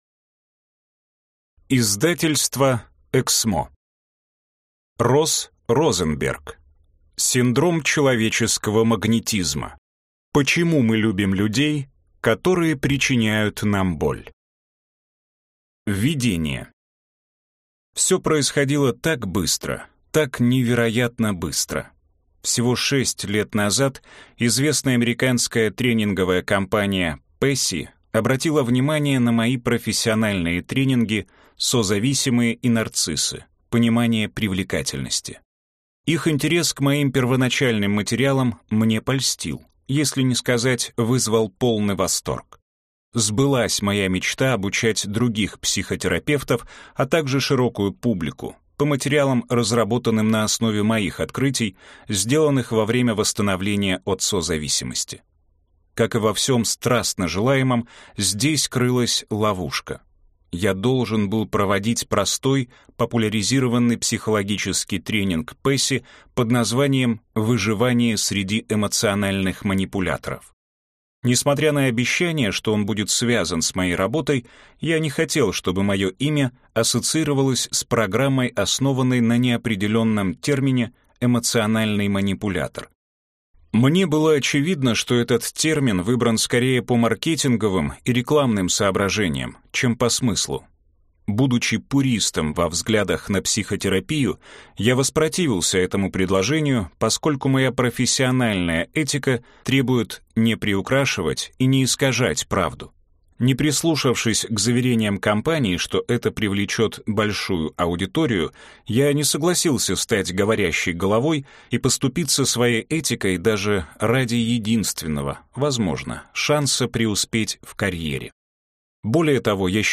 Аудиокнига Любовь или зависимость? Почему предрасположенность к нездоровым отношениям передается через поколения и как это остановить | Библиотека аудиокниг